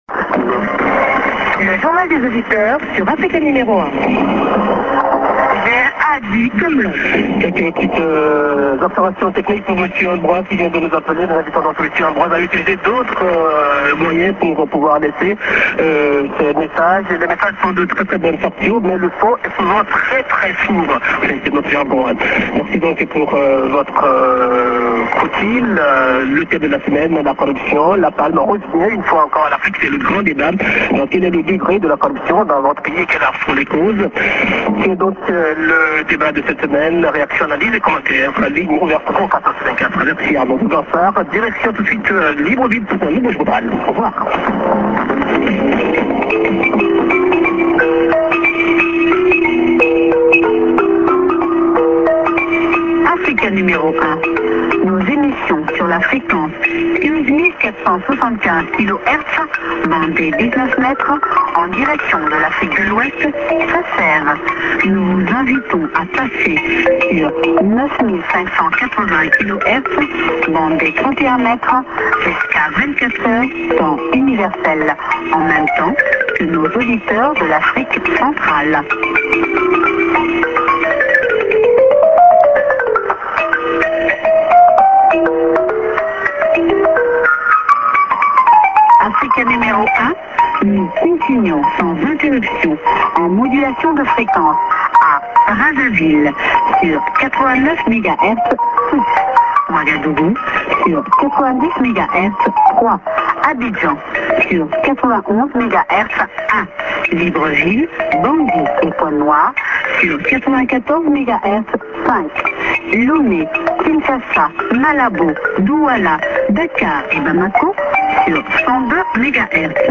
End ->IS->ID+SKJ(women)>IS->